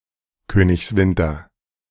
Königswinter (German pronunciation: [ˈkøːnɪçsˌvɪntɐ]
De-Königswinter.ogg.mp3